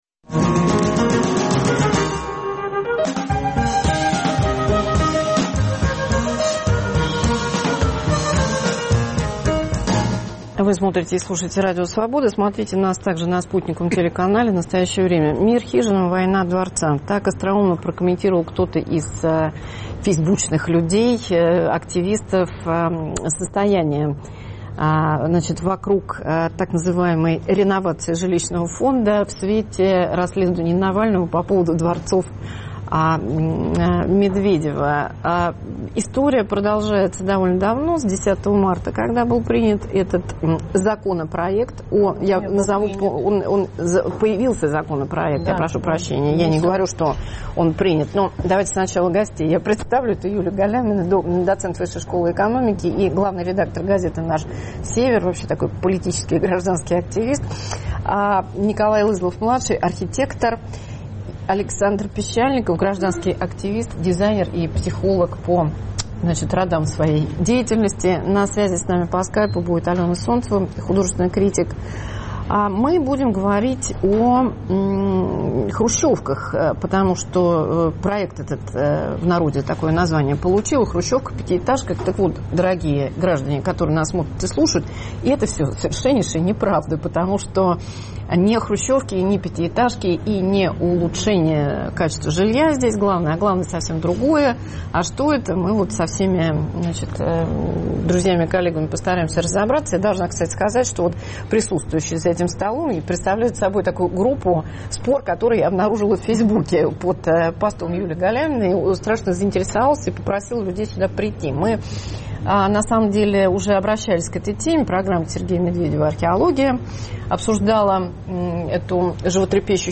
Рассуждают архитекторы и гражданские активисты.